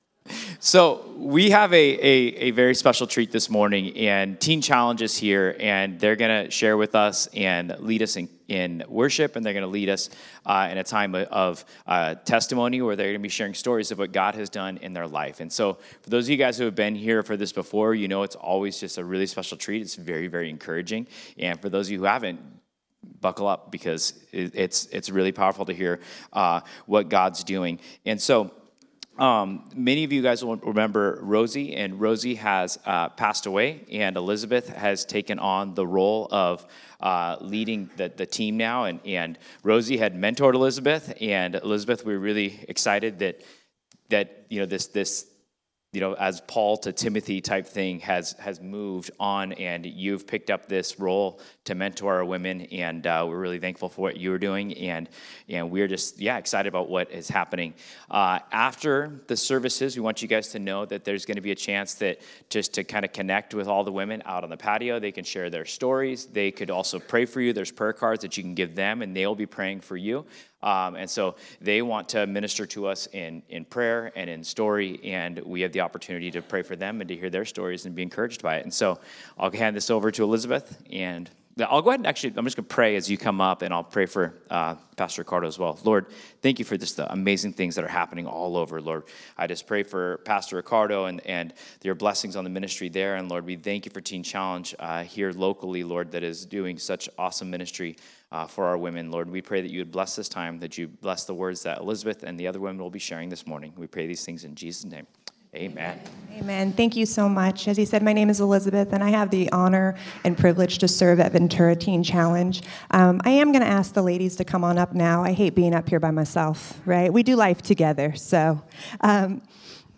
This Sunday, Teen Challenge will be leading our Sunday morning services.